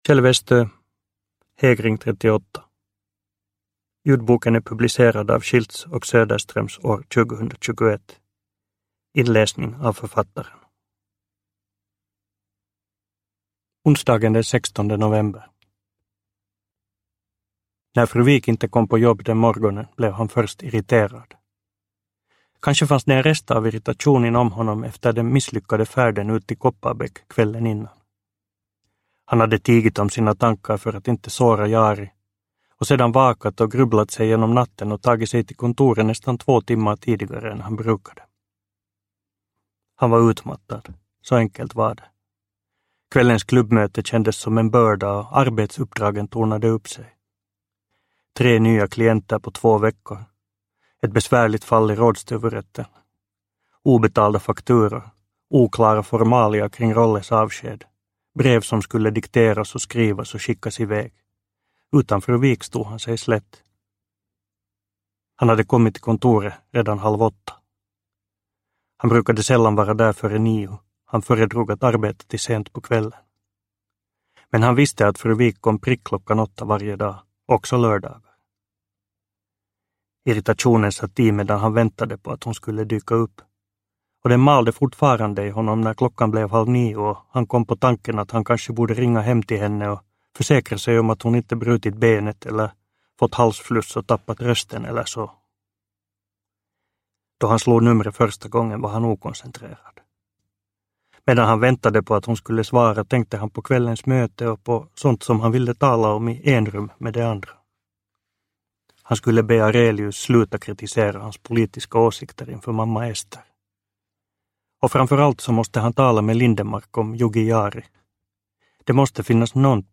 Hägring 38 – Ljudbok – Laddas ner
Uppläsare: Kjell Westö